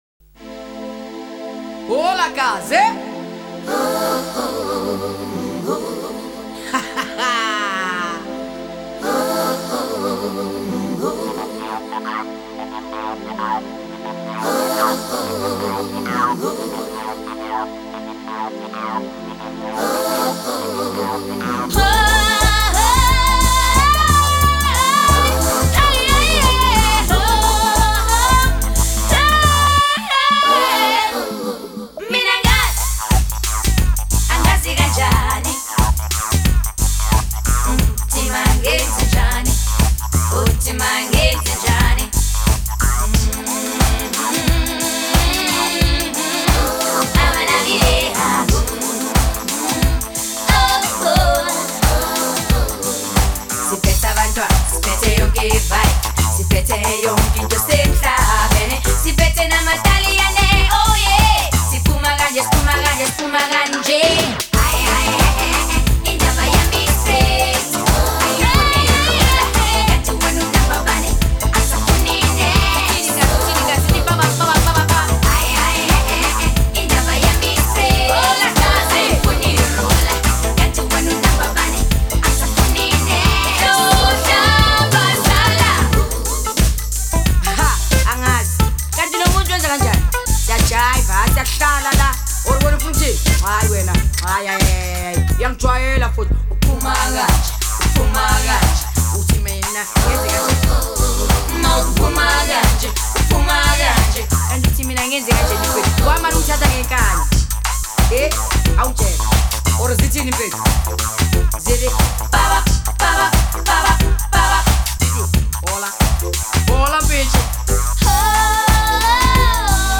vintage song
Genre : Afro House